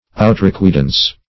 Search Result for " outrecuidance" : The Collaborative International Dictionary of English v.0.48: Outrecuidance \Ou`tre*cui`dance"\, n. [F., fr. outre beyond + cuider to think, L. cogitare.]